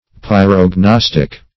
Search Result for " pyrognostic" : The Collaborative International Dictionary of English v.0.48: Pyrognostic \Pyr`og*nos"tic\, a. [Pyro- + Gr.